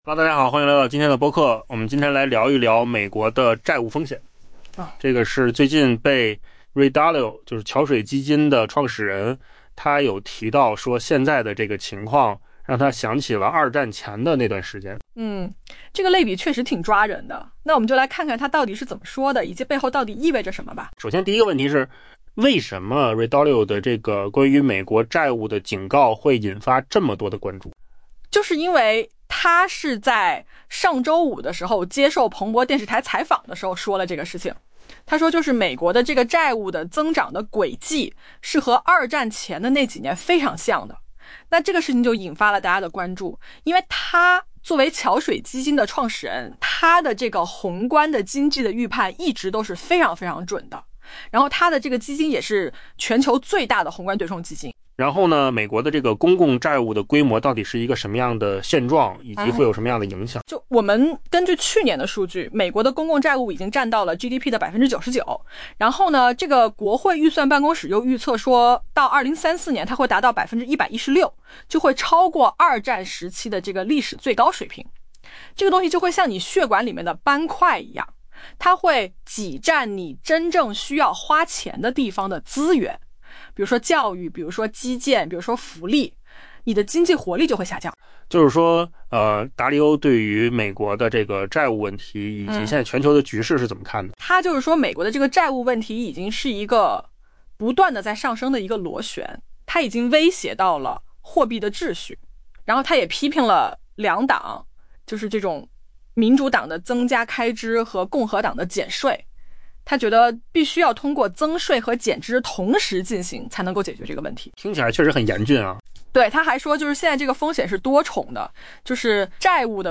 AI播客：换个方式听新闻 下载mp3